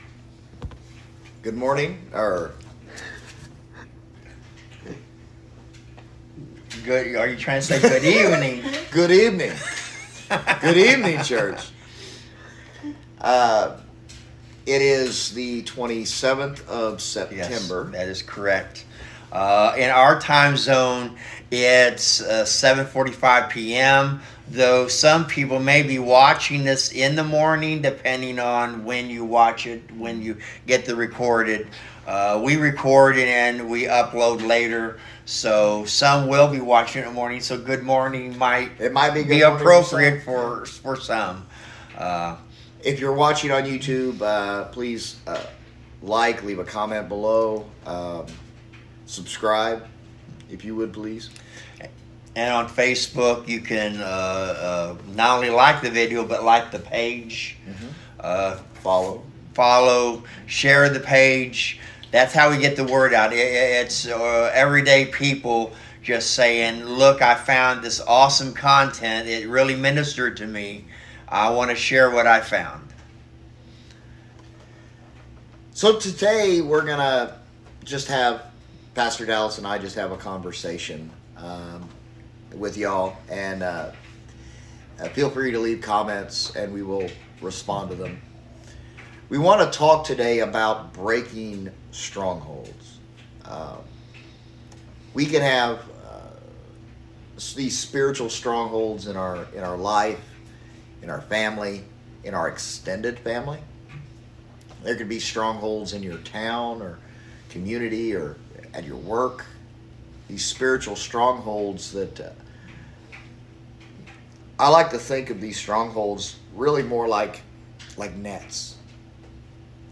Taking Down Strongholds – a Pastoral Discussion